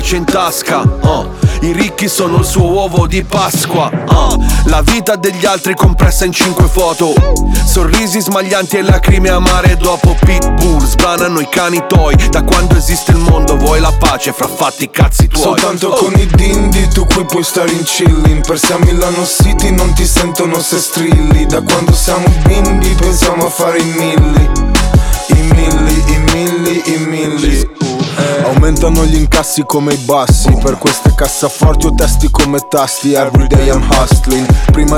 # Хип-хоп